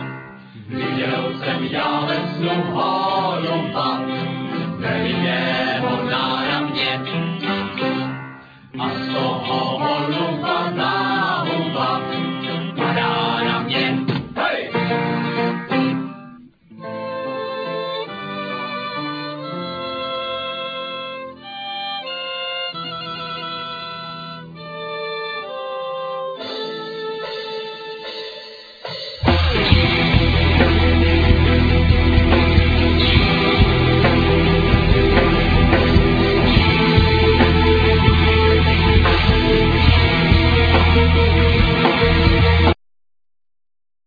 Vocal,Violin,Double bass,Percussion
Guitar,Vocal
Keyborard,Piano,Vocal
Drums,Vocal,Sampler